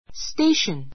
stéiʃən